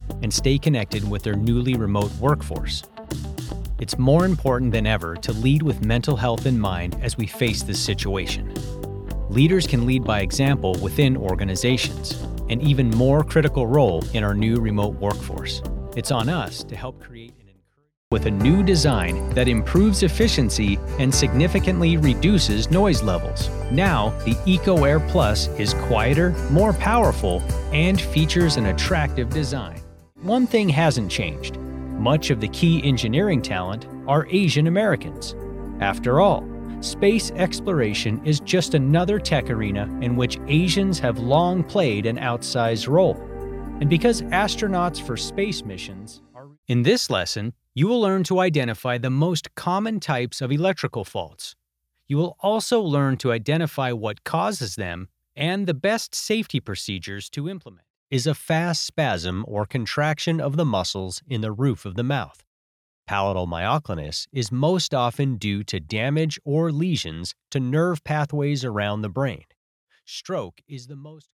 Male Voice Over Talent
Narration Demo
General American